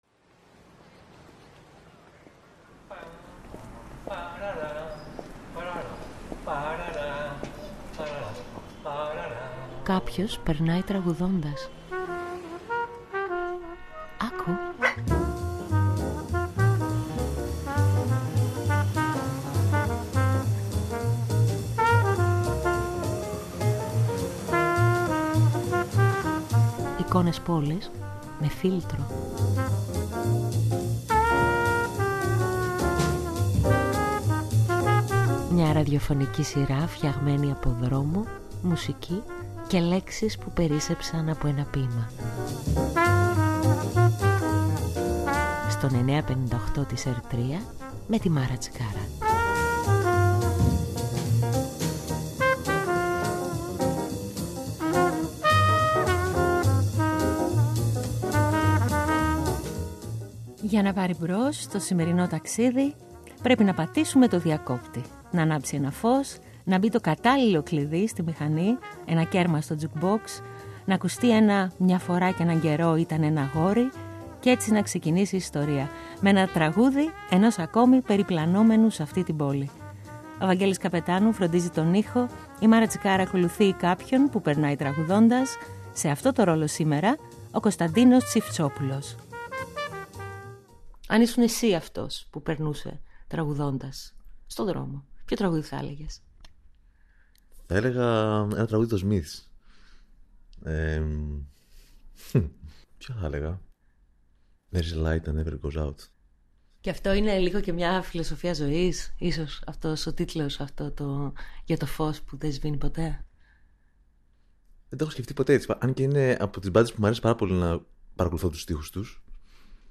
μια συνομιλία